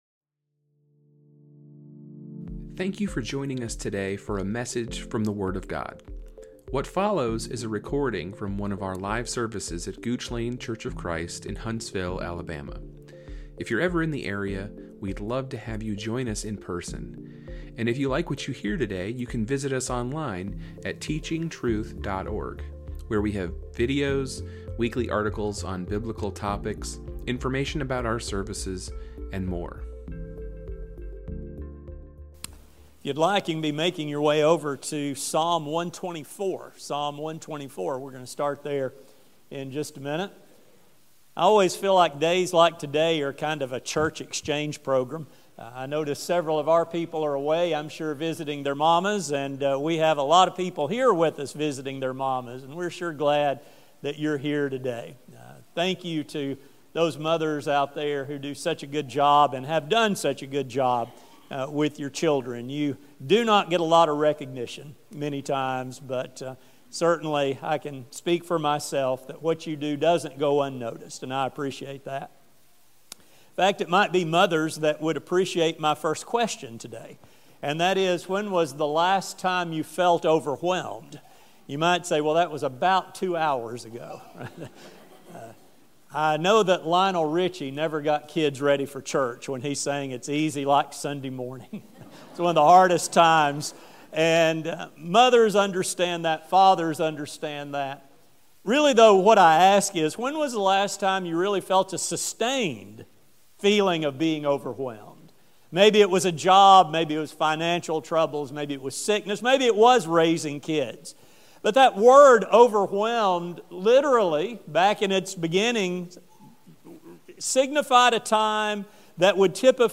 Along with the commands for holy conduct, He has included a number of promises to help as well as explaining the good that these commands can accomplish. A sermon